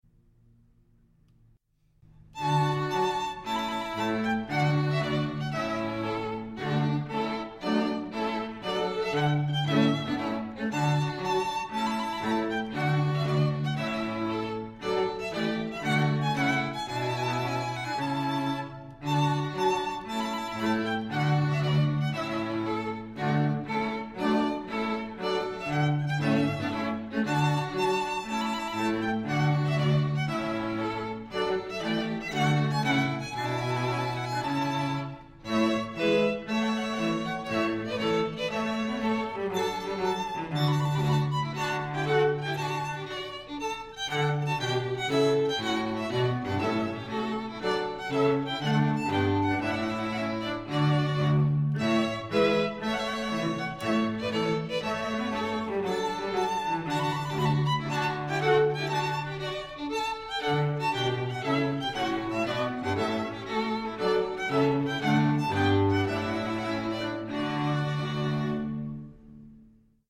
Elysium String Quartet – performing throughout the Lehigh Valley, Delaware Valley, and Philadelphia, PA